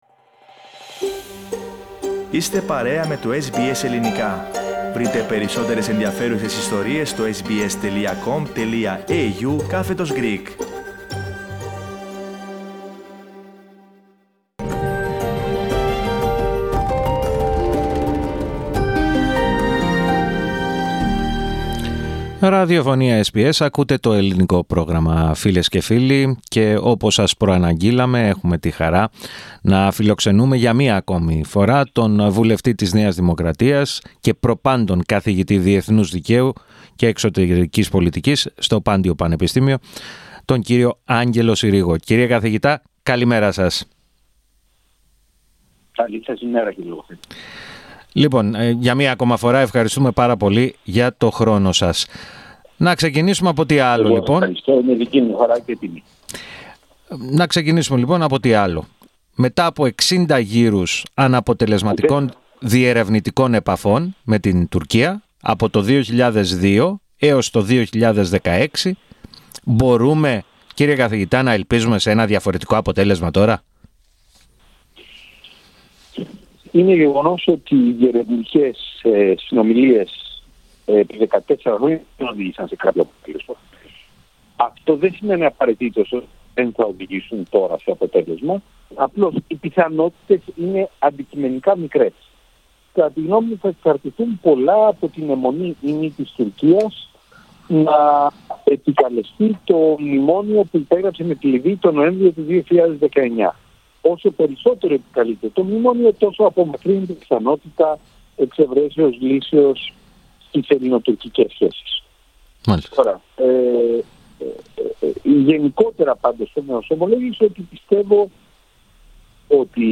Στην επανέναρξη των διερευνητικών επαφών Ελλάδας – Τουρκίας και στην πιο διαλλακτική στάση που εμφανίζει ο Τούρκος πρόεδρος Ρετζέπ Ταγίπ Ερντογάν αναφέρθηκε ο αναπληρωτής καθηγητής Διεθνούς Δικαίου και Εξωτερικής Πολιτικής, στο Πάντειο Πανεπιστήμιο, Άγγελος Συρίγος, σε συνέντευξή του στο Ελληνικό Πρόγραμμα της Ραδιοφωνίας SBS.